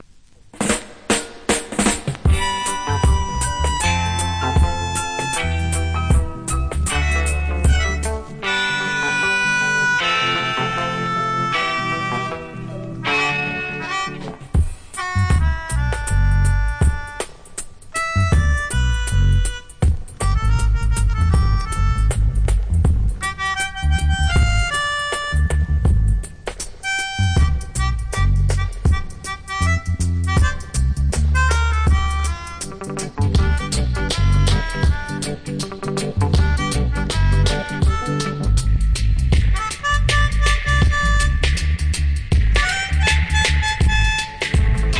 Wicked Reggae Horn Inst.